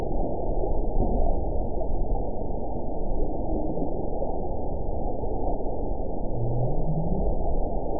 event 921162 date 05/01/24 time 05:33:09 GMT (1 year ago) score 9.30 location TSS-AB04 detected by nrw target species NRW annotations +NRW Spectrogram: Frequency (kHz) vs. Time (s) audio not available .wav